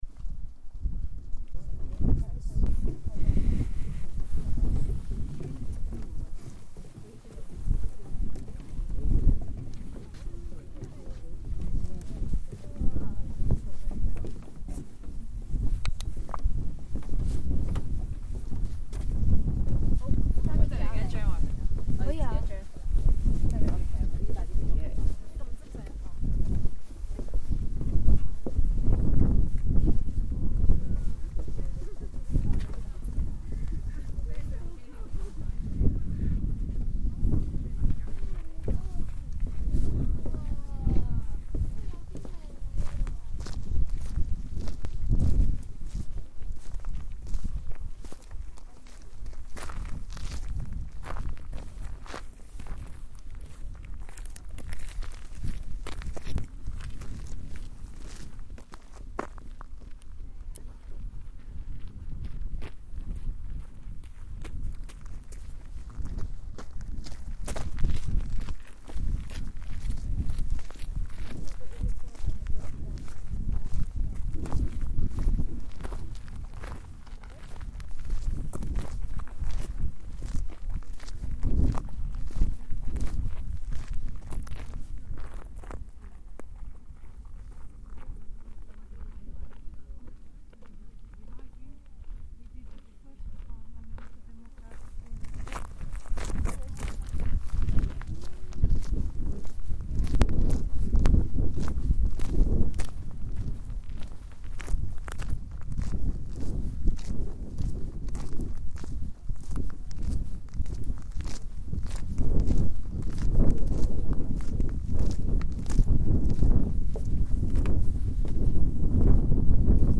Stone paths in Thingvellir